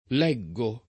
leggere [l$JJere] v.; leggo [